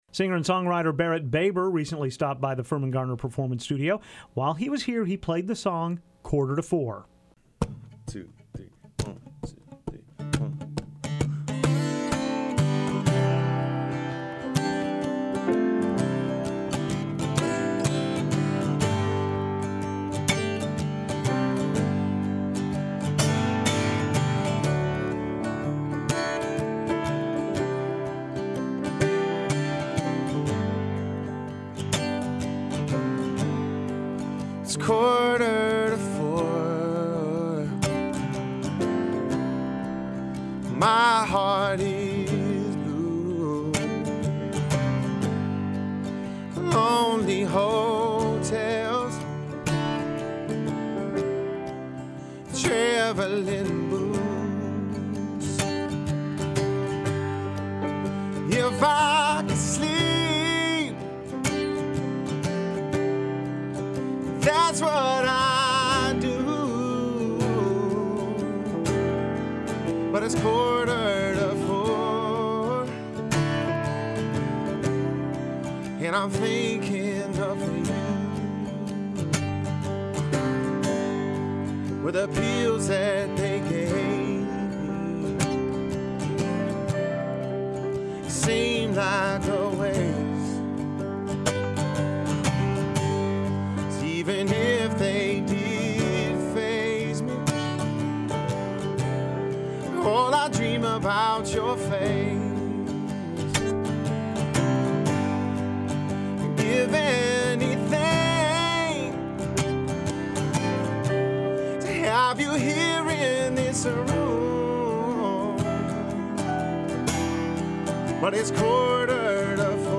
inside the Firmin-Garner Performance Studio